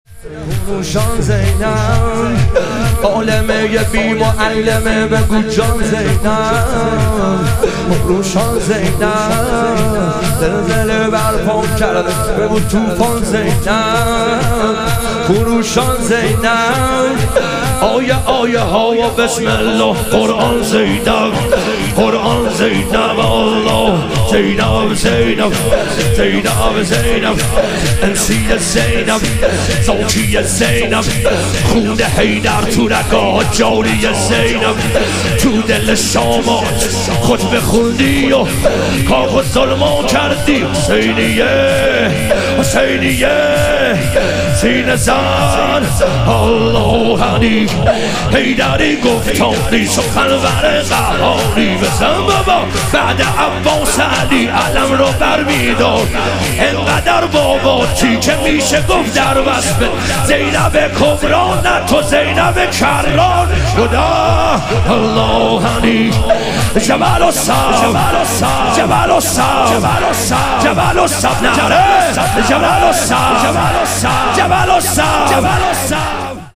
شهادت حضرت زینب کبری علیها سلام - شور